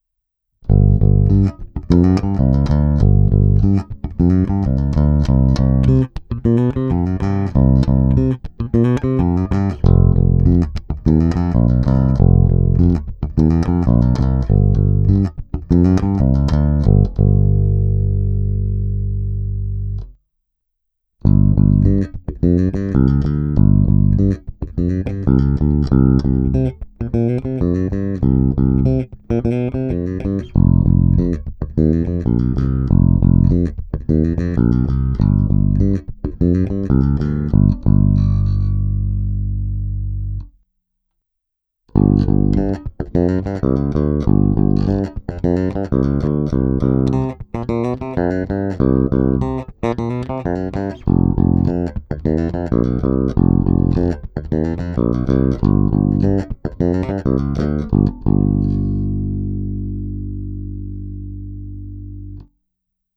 Není-li uvedeno jinak, následující nahrávky jsou provedeny rovnou do zvukové karty, jen normalizovány, jinak ponechány bez úprav.
Hráno vždy nad aktivním snímačem, v případě obou pak mezi nimi.